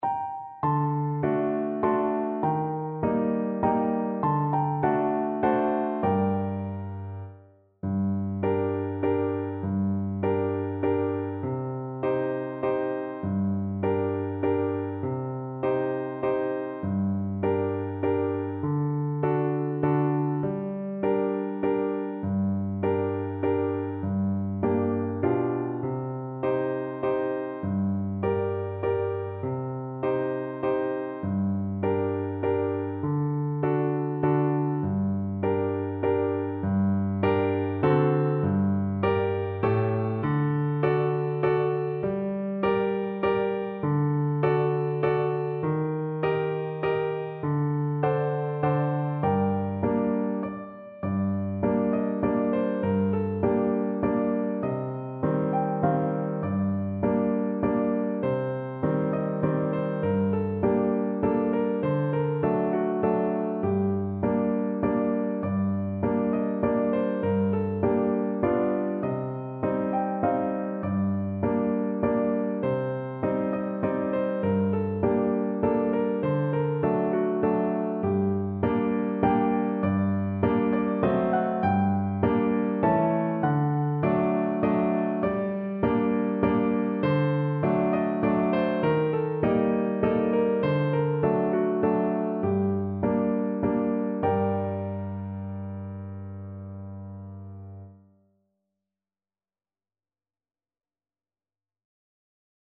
G minor (Sounding Pitch) (View more G minor Music for Flute )
Moderato =c.100
Traditional (View more Traditional Flute Music)